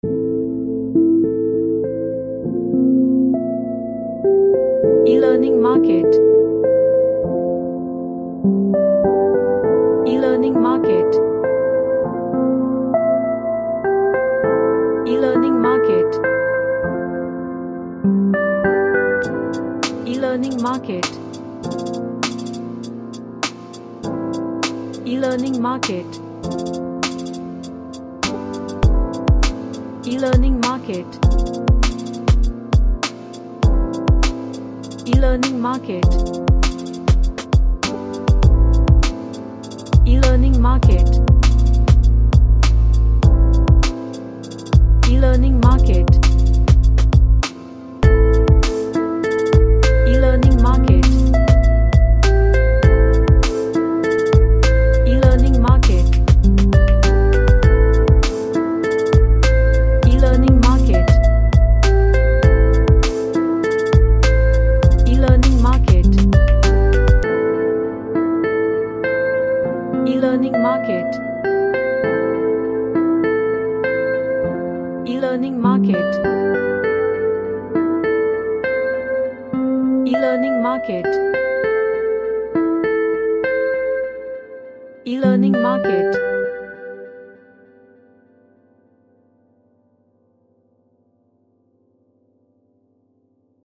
A chill type pop track
Chill Out